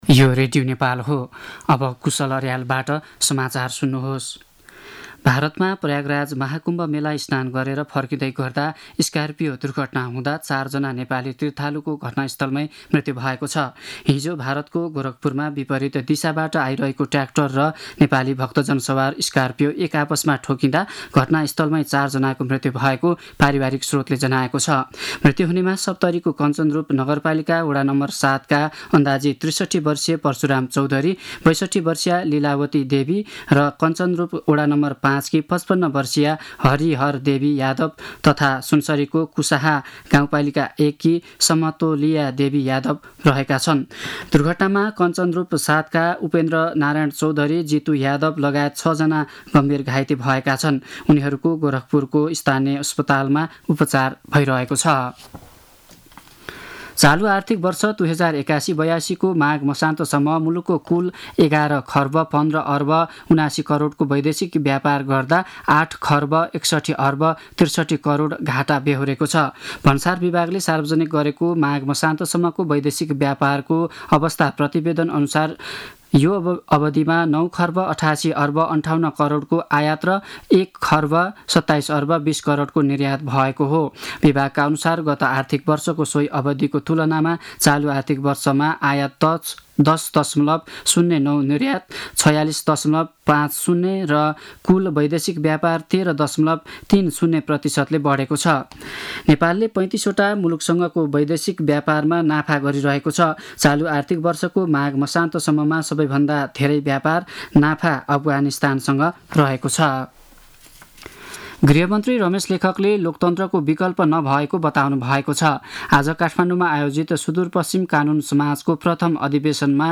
दिउँसो १ बजेको नेपाली समाचार : ११ फागुन , २०८१
1-pm-news-7.mp3